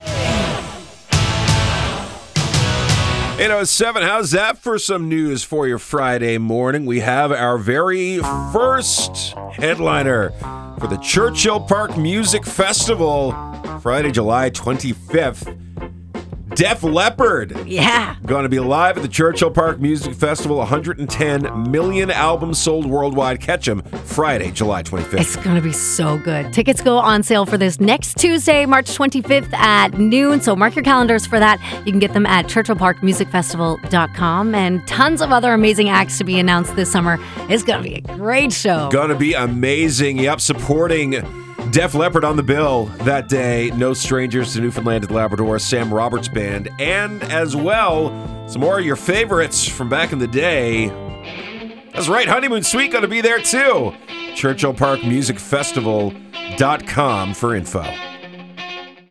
def-leppard-announce.mp3